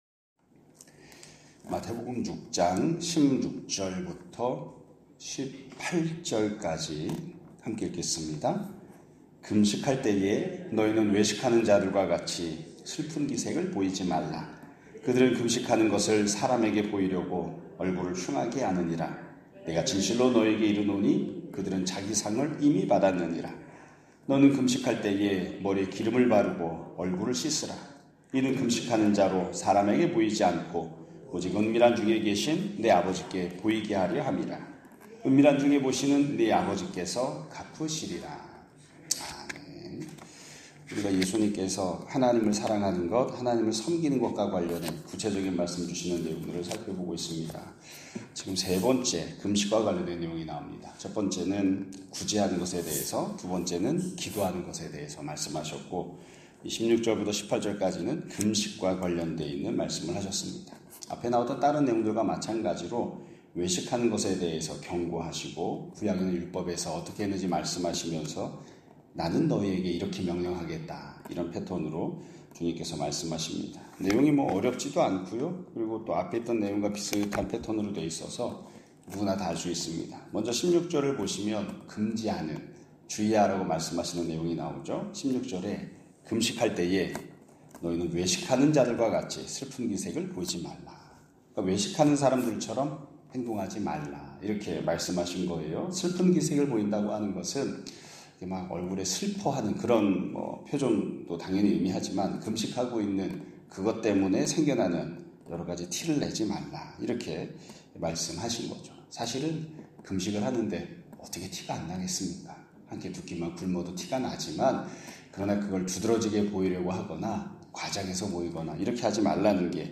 2025년 6월 17일(화요일) <아침예배> 설교입니다.